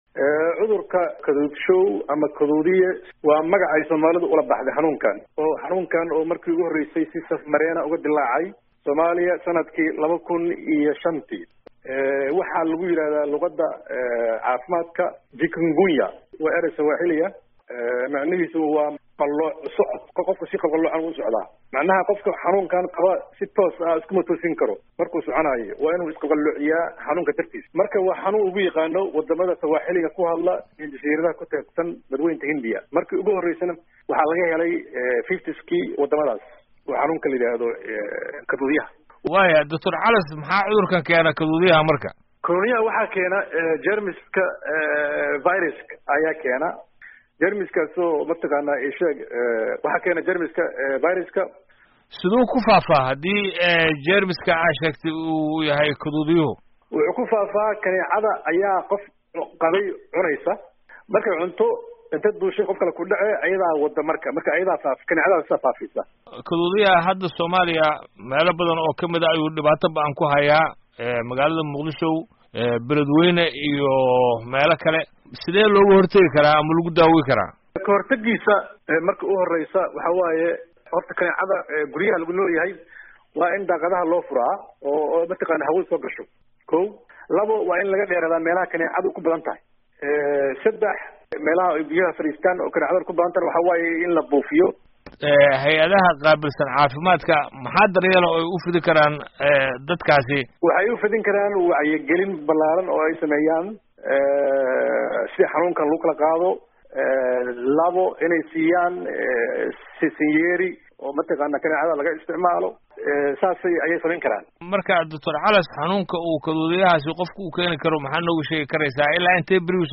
Wareysi: Xanuunka Kaduudiyaha